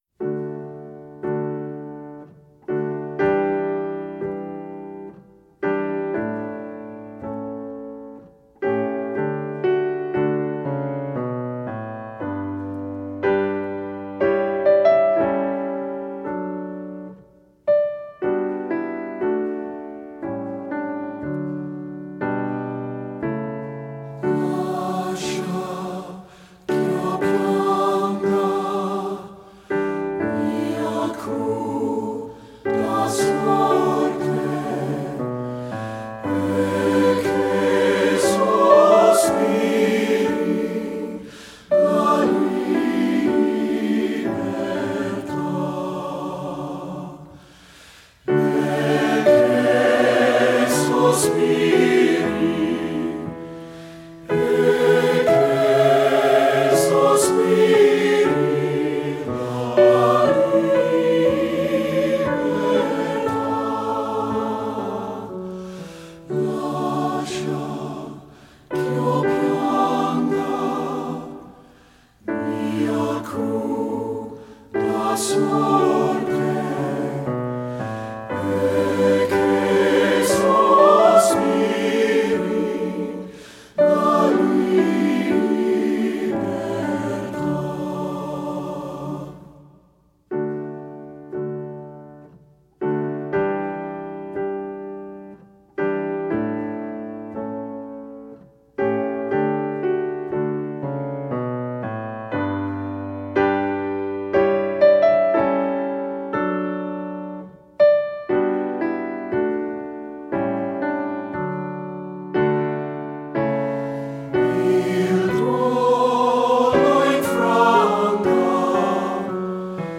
secular choral
3-Part Mixed (SATB recording)